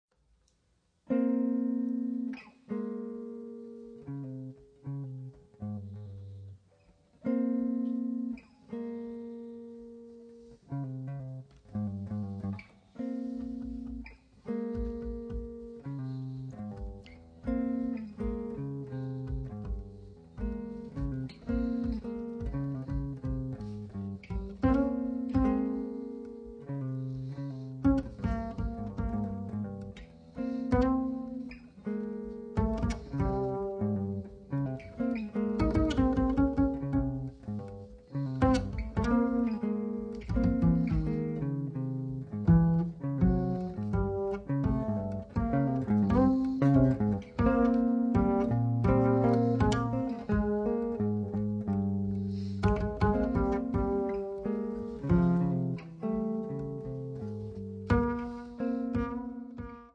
chitarra
contrabbasso